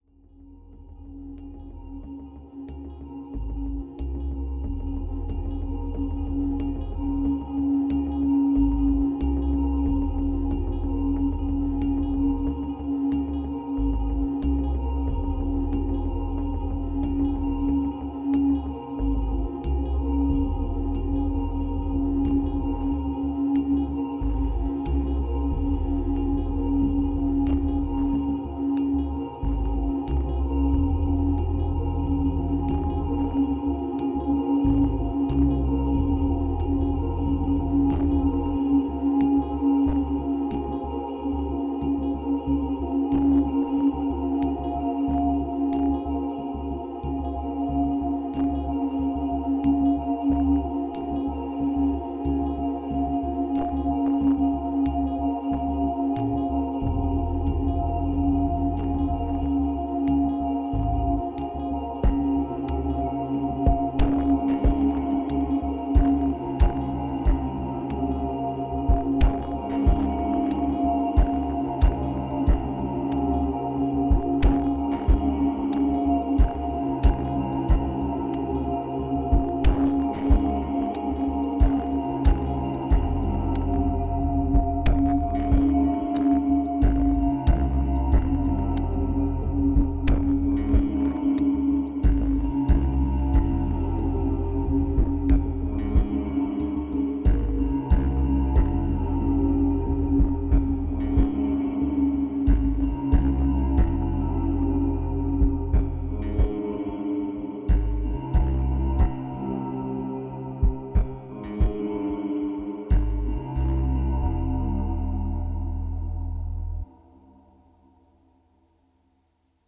interlude
2391📈 - 77%🤔 - 92BPM🔊 - 2017-06-05📅 - 724🌟